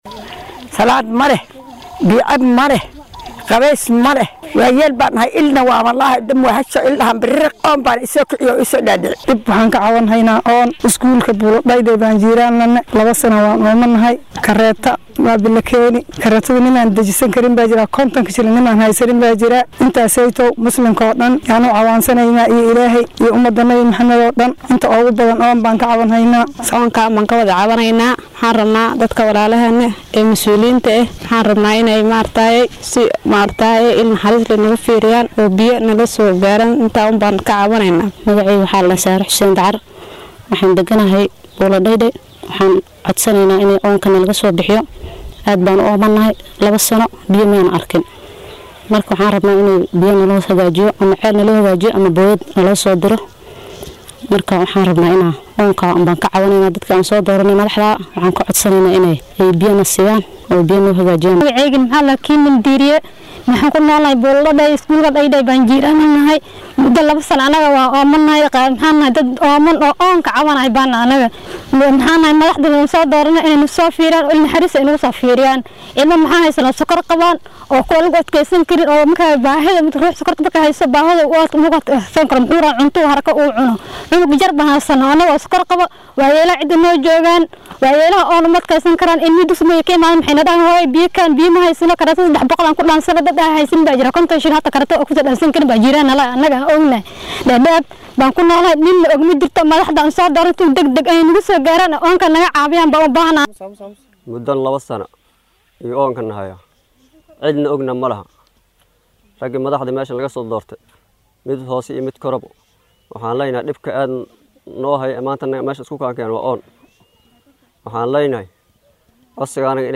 Qaar ka mid ah dadweynaha ku nool xaafadda Buula Dhaydhay ee magaalada Dadaab oo la hadlay warbaahinta Star ayaa cabasho ka muujiyey biyo la’aan muddo haysatay, waxayna ma’suuliyiinta deegaanka iyo dowladdaba ka codsadeen in wax laga qabto cabashadooda.